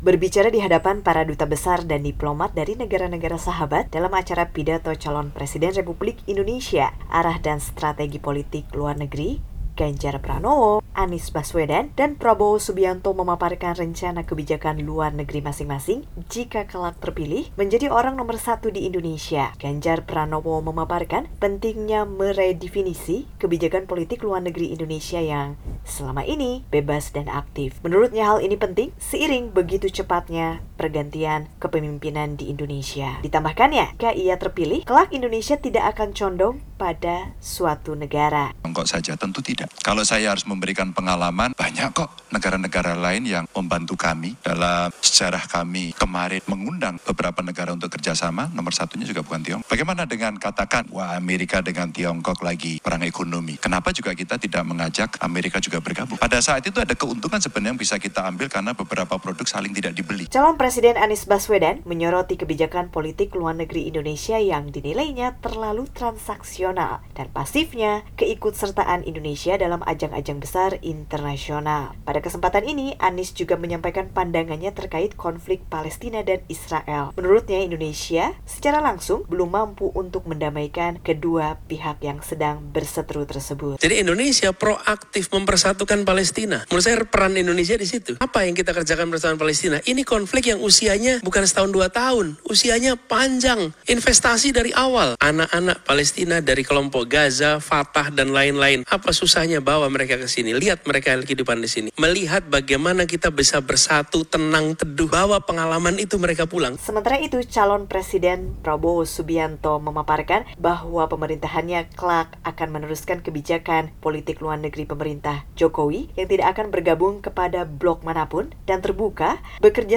Berbicara di hadapan para dubes dan diplomat dari negara-negara sahabat dalam “Pidato Calon Presiden Republik Indonesia: Arah dan Strategi Politik Luar Negeri,” Ganjar Pranowo, Anies Baswedan dan Prabowo Subianto memaparkan rencana kebijakan luar negeri masing-masing jika terpilih jadi Presiden.